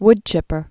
(wdchĭpər)